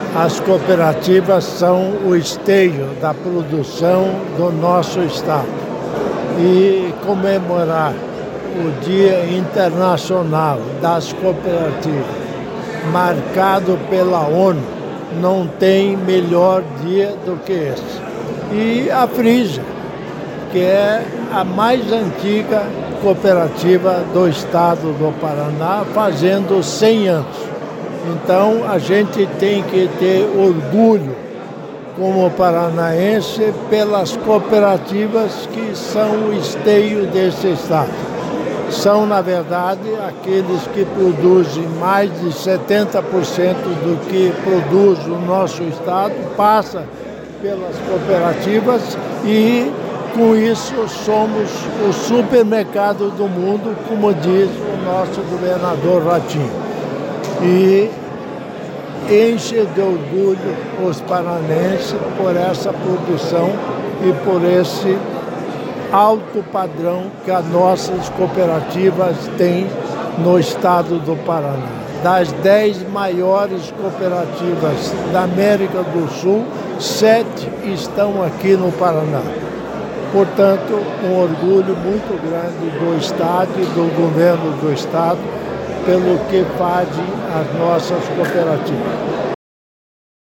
Sonora do governador em exercício Darci Piana sobre o Ano Internacional do Cooperativismo e os 100 anos da Cooperativa Frísia